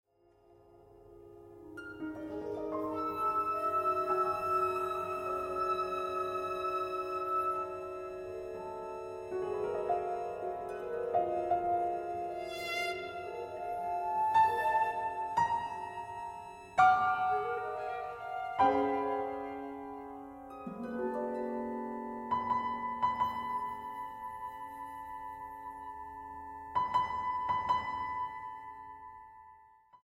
Música de Cámara